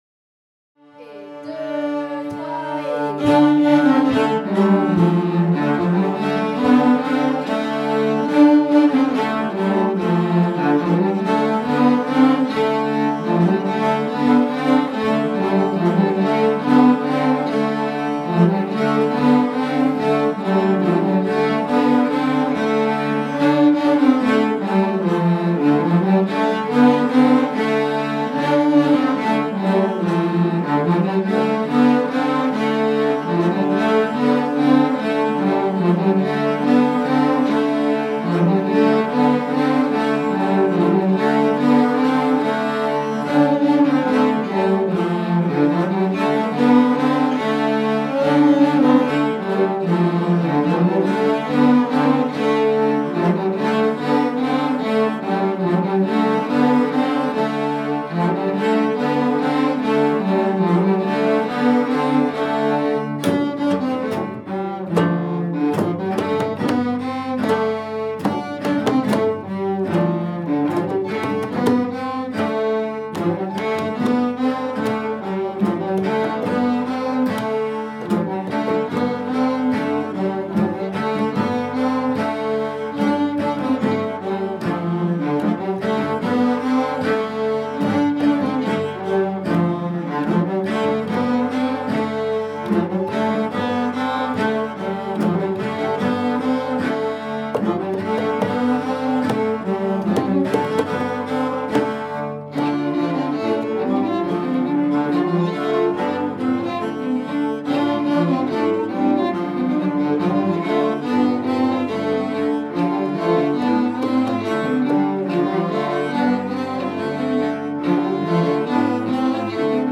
06_studio_violoncelles-hanter_dro.mp3